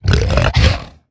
Minecraft Version Minecraft Version snapshot Latest Release | Latest Snapshot snapshot / assets / minecraft / sounds / mob / hoglin / angry1.ogg Compare With Compare With Latest Release | Latest Snapshot
angry1.ogg